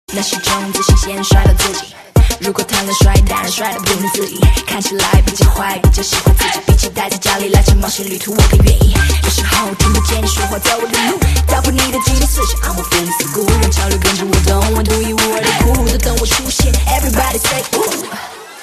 M4R铃声, MP3铃声, 欧美歌曲 72 首发日期：2018-05-14 11:16 星期一